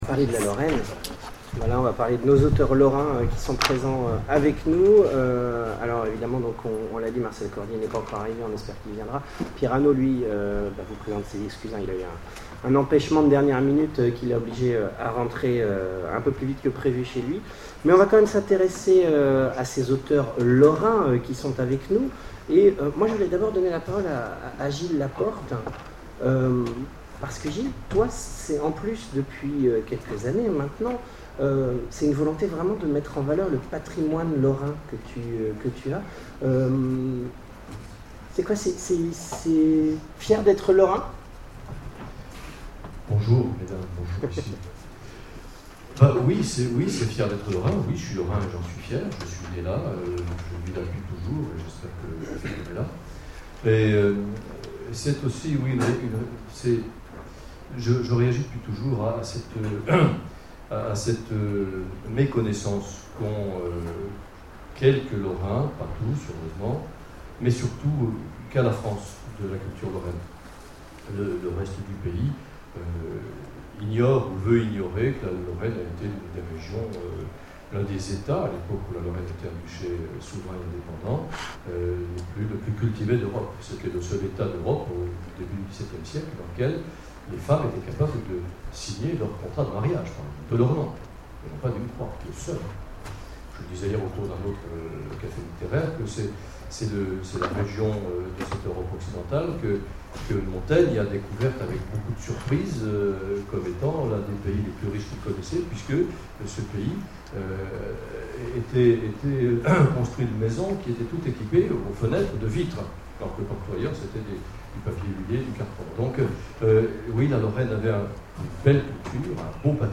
Imaginales 2012 : Conférence Nos auteurs lorrains...